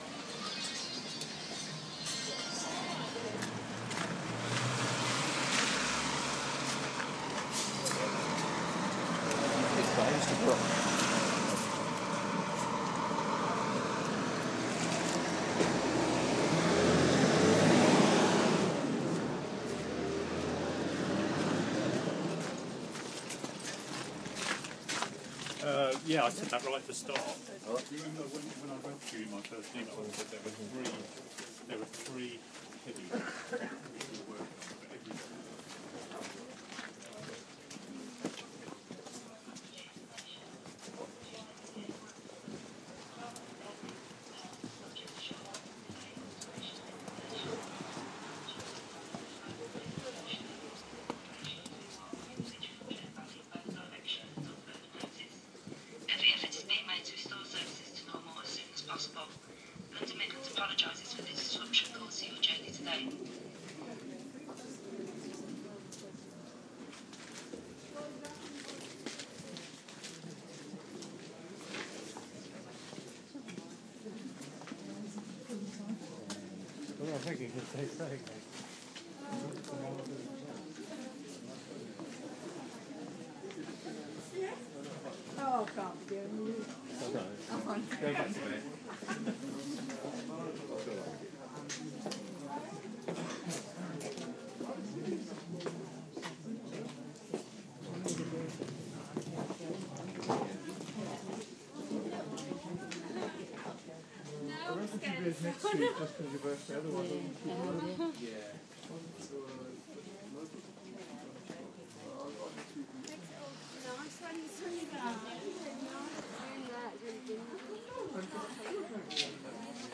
Sounds at the station (cancelled trains)
99232-sounds-at-the-station-cancelled-trains.mp3